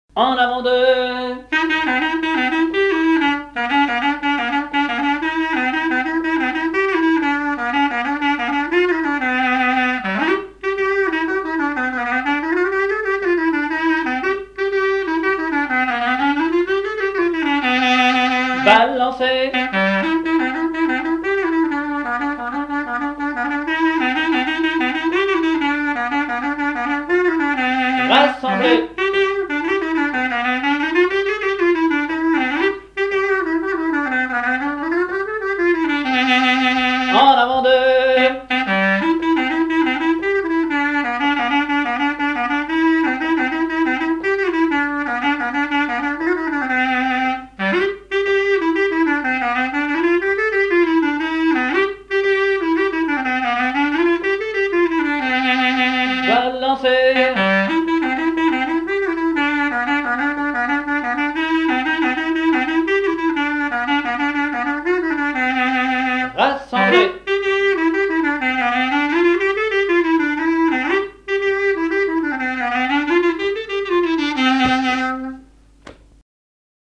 danse : branle : avant-deux
Pièce musicale inédite